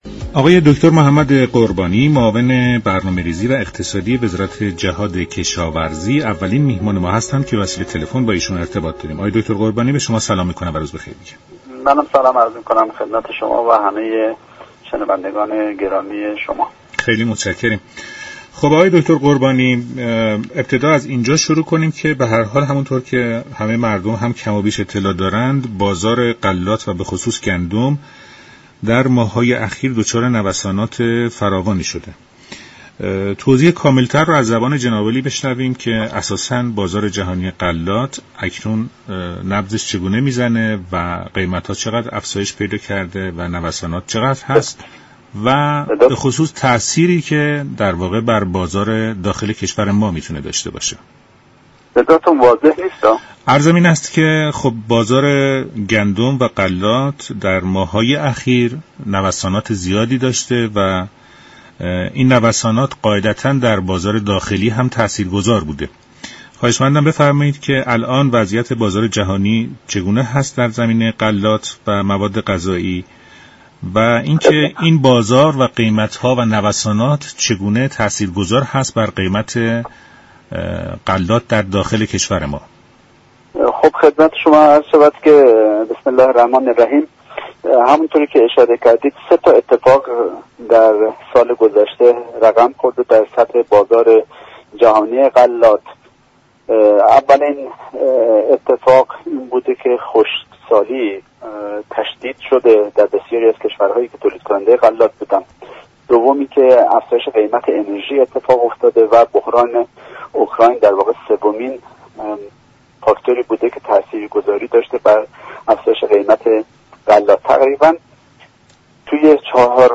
به گزارش شبكه رادیویی ایران، محمد قربانی معاون برنامه ریزی و اقتصادی وزارت جهاد كشاورزی در برنامه «ایران امروز» به وضعیت بازار جهانی غلات و گندم و گرانی های اخیر آن پرداخت و گفت: تشدید خشكسالی در كشورهای تولیدكننده غلات، افزایش حامل های انرژی و جنگ اوكراین و روسیه، سبب گرانی غلات و گندم در جهان شده است.